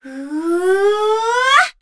Kara-Vox_Casting2.wav